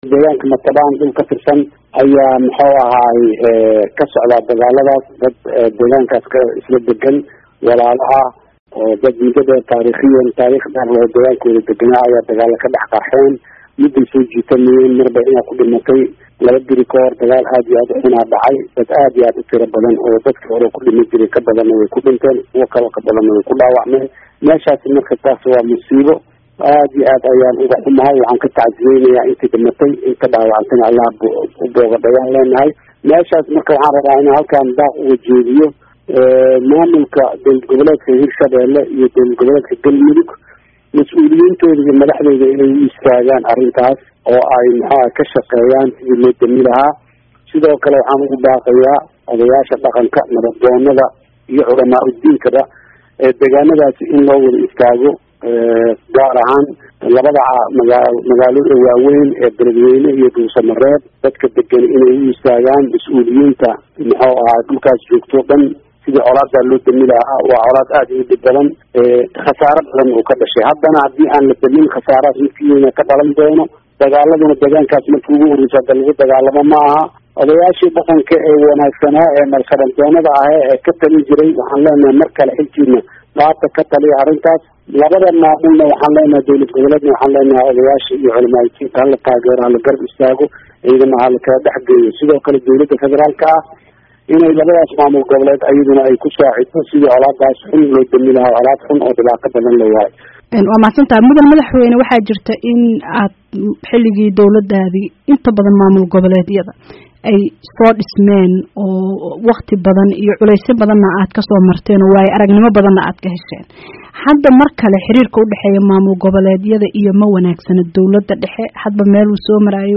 Wareysi: Madaxweyne Xasan Sheekh Maxamuud